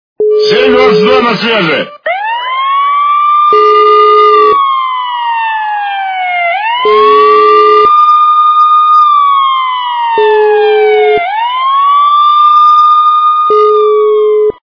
» Звуки » Авто, мото » Голос по рации и сирена - 7-22 на связи...
При прослушивании Голос по рации и сирена - 7-22 на связи... качество понижено и присутствуют гудки.